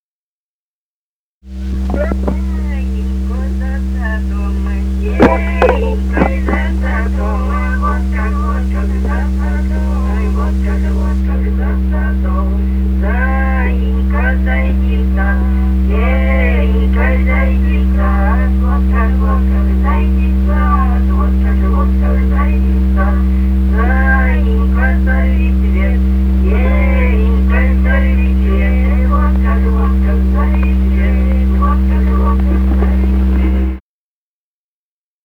Русские народные песни Красноярского края.
«[Ах], заинька за садом» (вечёрочная). с. Денисово Дзержинского района. Пела группа колхозников